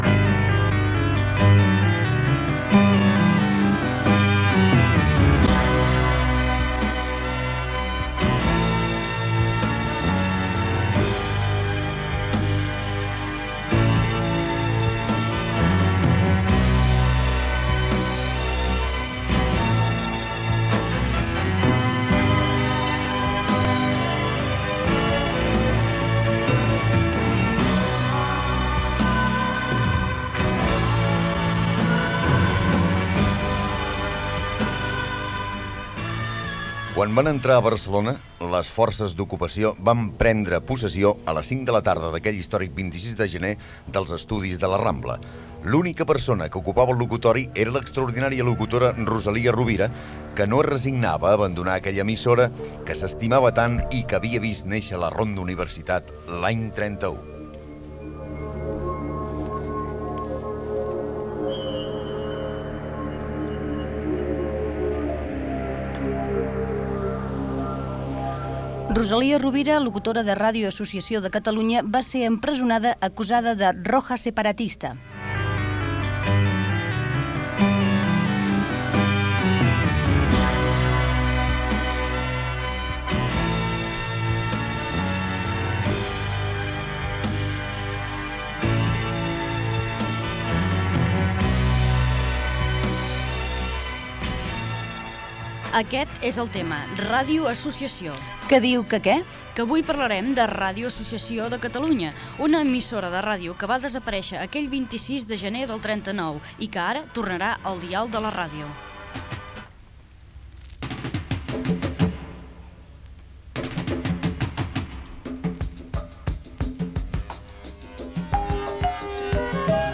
Indicatiu de RAC 105 que en uns dies començarà a emetre. Crèdits i sintonia de l'emissora Gènere radiofònic Divulgació